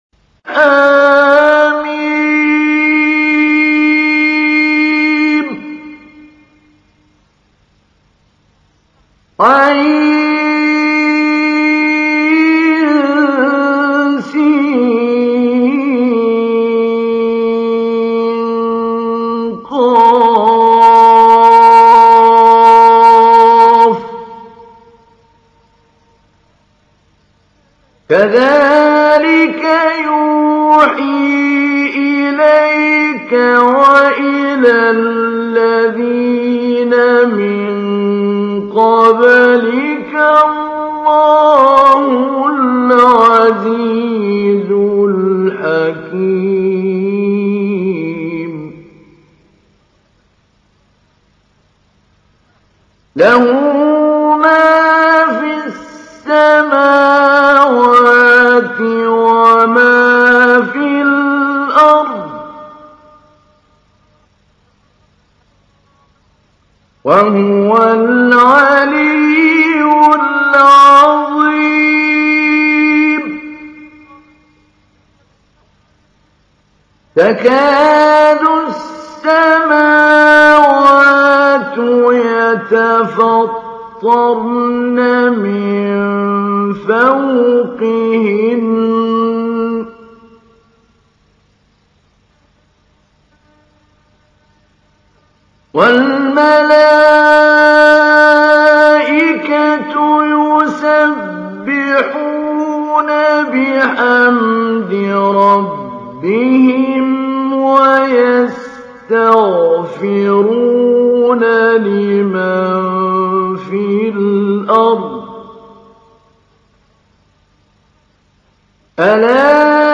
تحميل : 42. سورة الشورى / القارئ محمود علي البنا / القرآن الكريم / موقع يا حسين